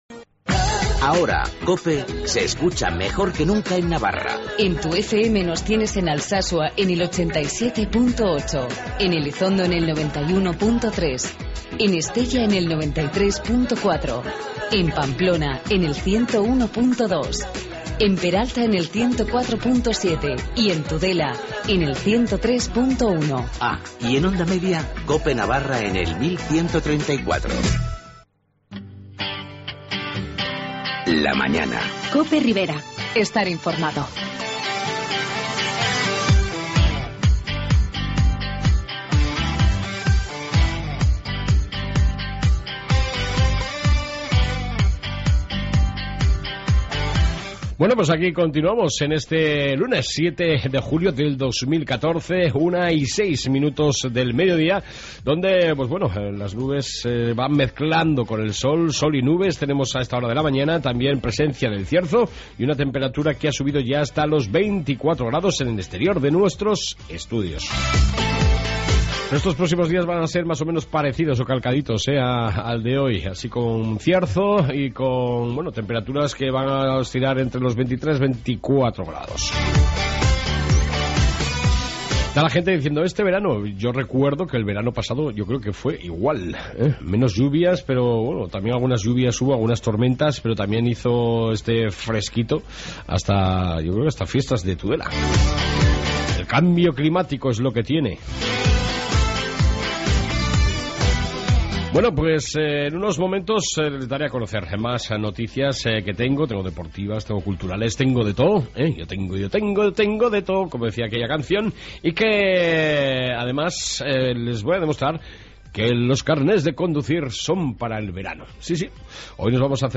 AUDIO: En esta 2 parte Toda la Información de la Ribera Y entrevista sobre las Auto escuelas en verano